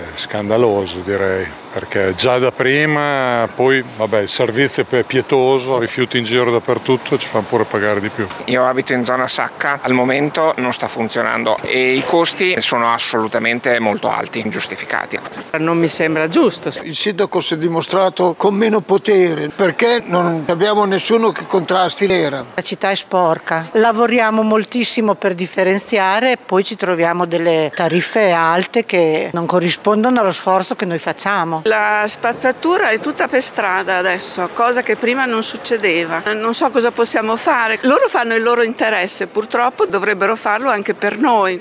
Le interviste
Una modalità che non premia i virtuosi e cresce sempre di più il malumore tra i cittadini come testimoniano queste interviste:
VOX-AUMENTI-TARIFFA.mp3